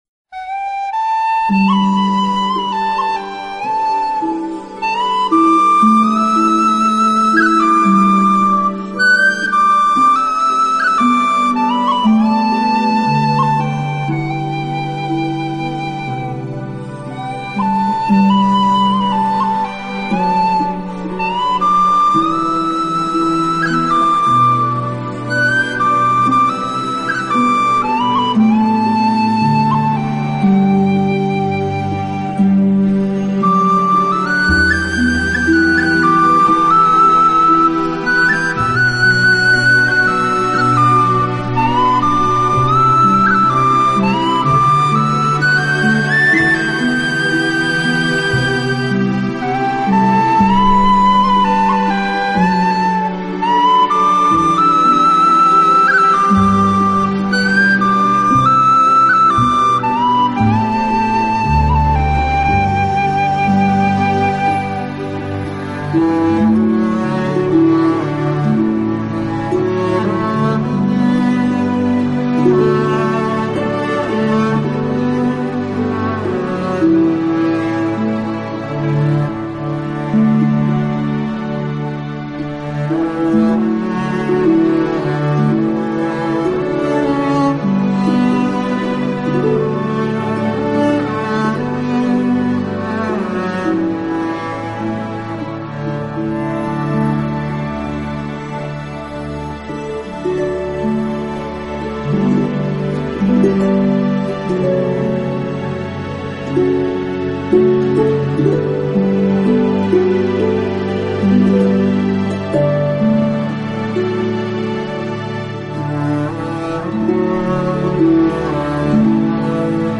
苏格兰如泣如诉的风笛之精选